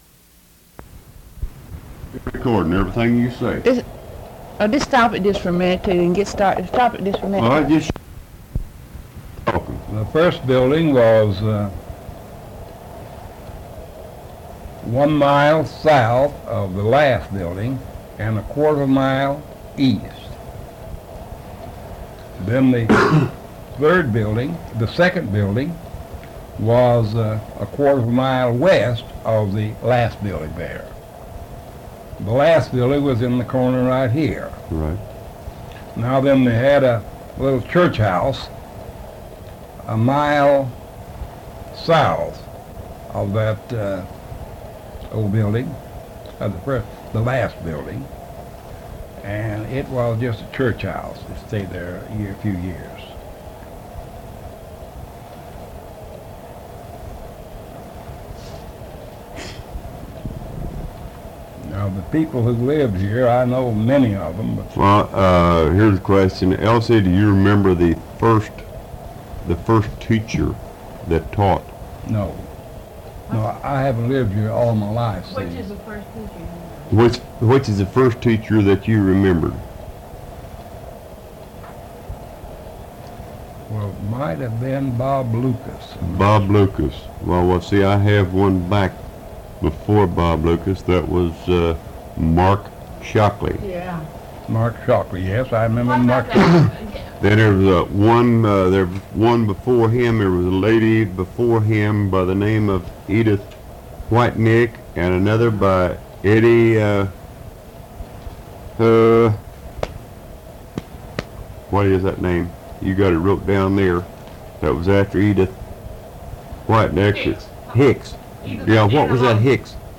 Oral History Archive | Pinehill Community